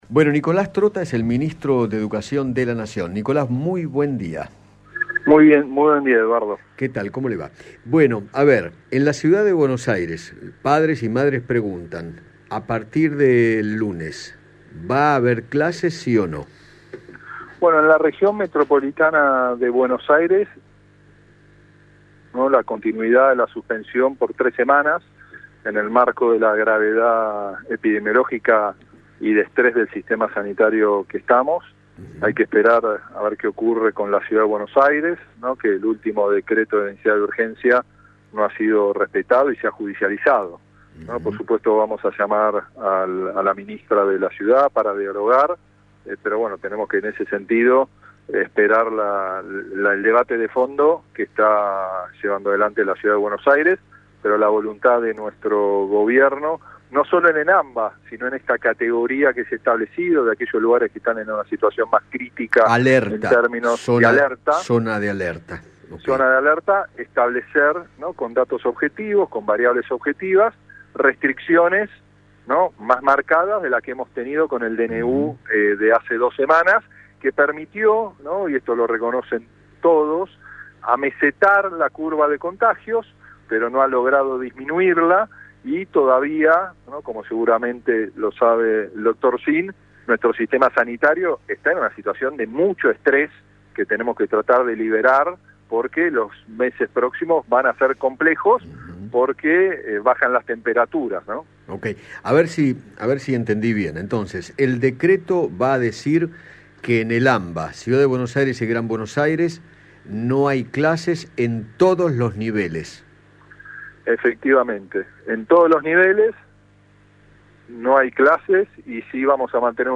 Nicolás Trotta, ministro de Educación de la Nación, dialogó con Eduardo Feinmann sobre el nuevo DNU con más restricciones y aseguró que seguirá la suspensión de clases por  3 semanas más.